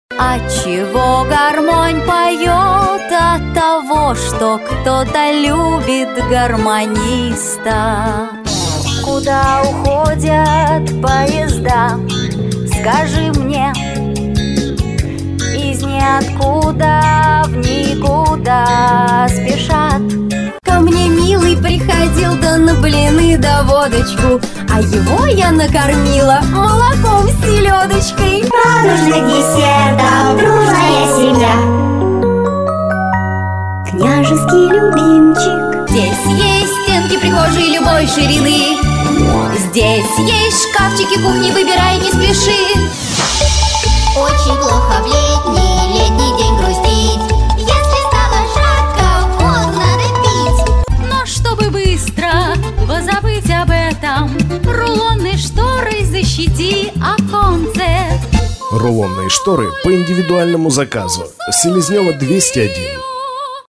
Диапазон: мальчики и девочки разного возраста, девушки, женщины, бабушки, сказочные, мультяшные персонажи.
микрофоны: beyerdynamic opus81 предусилители: behringer mic200 другое оборудование: звуковая карта line6 gear box компрессор dbx 266xl